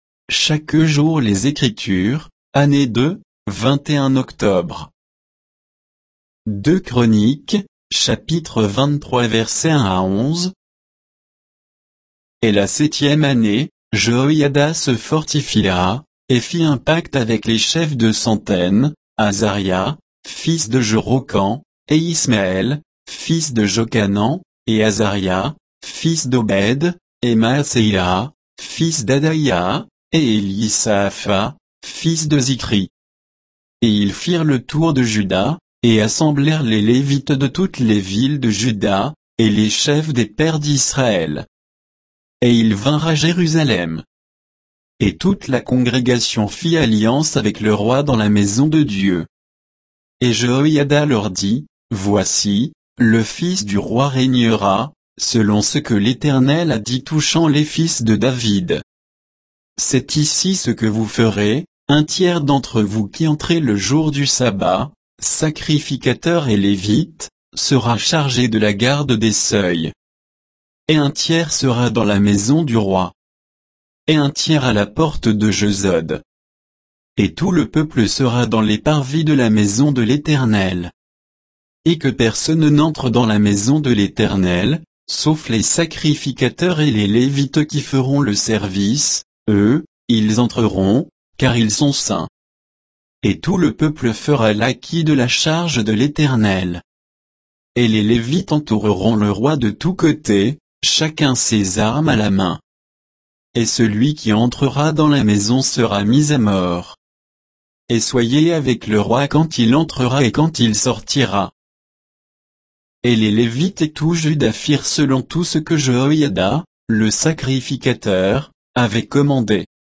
Méditation quoditienne de Chaque jour les Écritures sur 2 Chroniques 23, 1 à 11